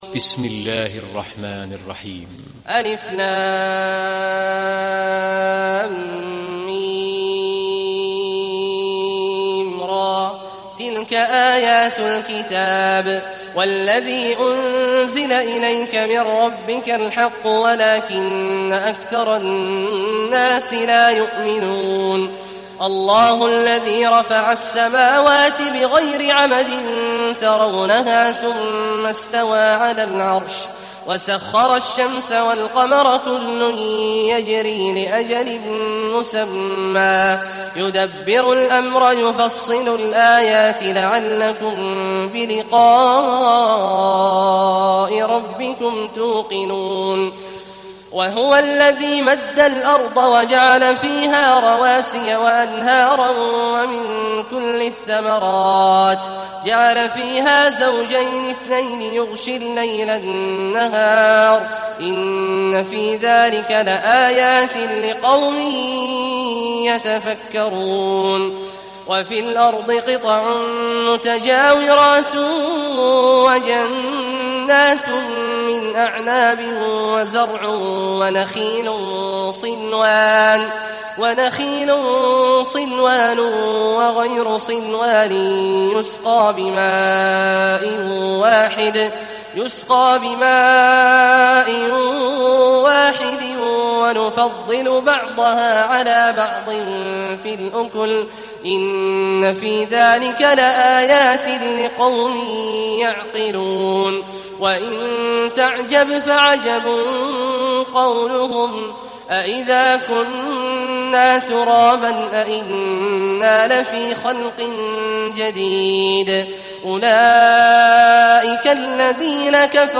Rad Suresi İndir mp3 Abdullah Awad Al Juhani Riwayat Hafs an Asim, Kurani indirin ve mp3 tam doğrudan bağlantılar dinle